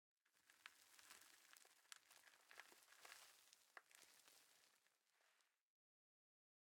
sounds / block / sand
sand21.ogg